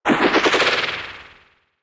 snd_petrify.wav